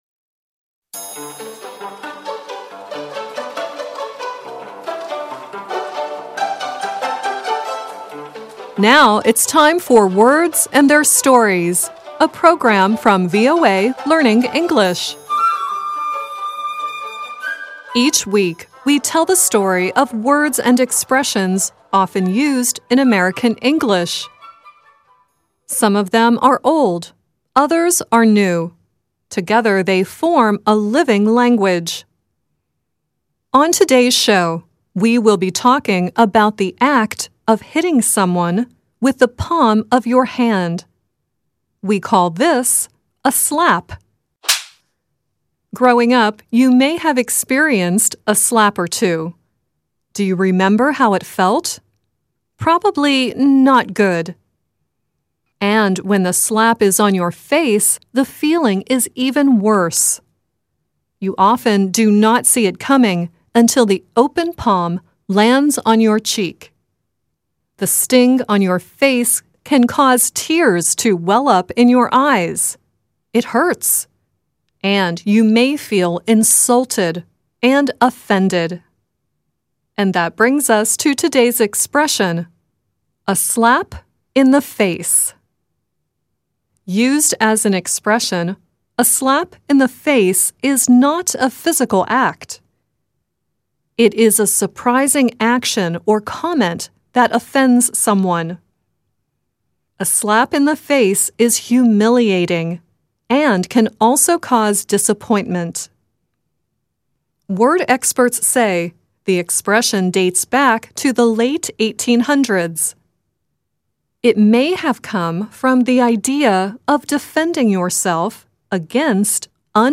At the end of the program, the rock band Kiss sings their 1989 song "Love's a Slap in the Face."